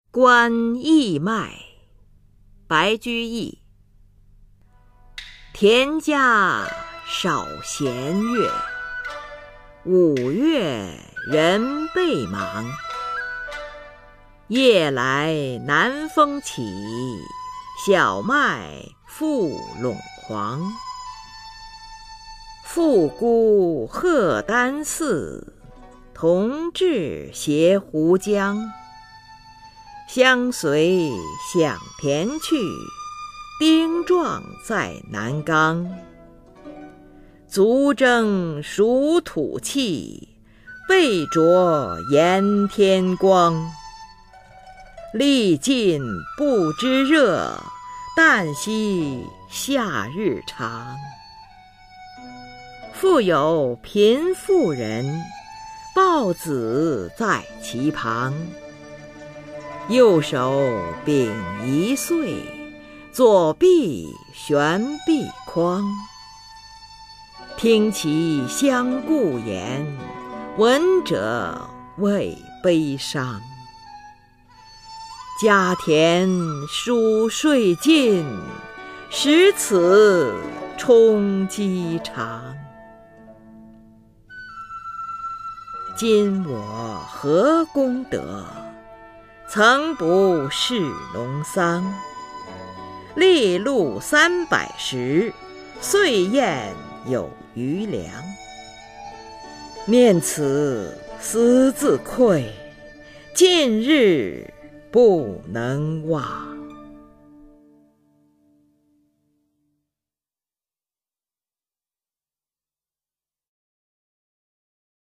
[隋唐诗词诵读]白居易-观刈麦 唐诗诵读